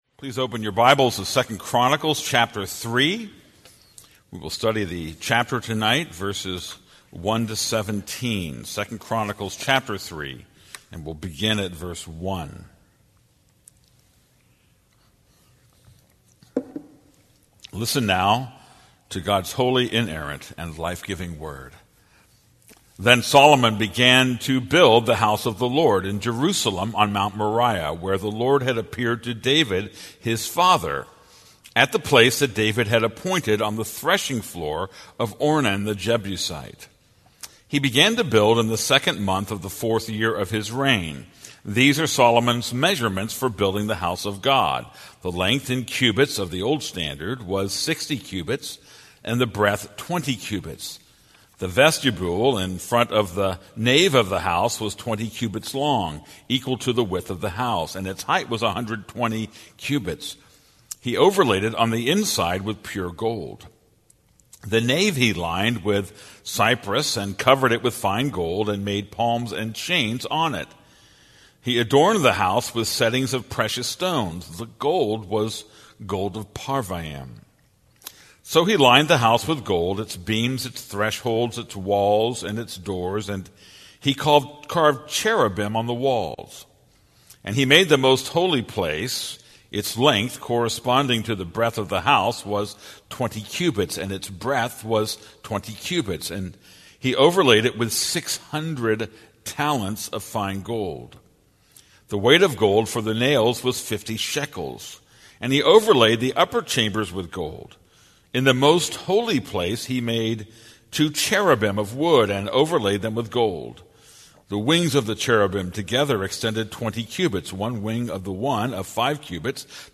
This is a sermon on 2 Chronicles 3:1-17.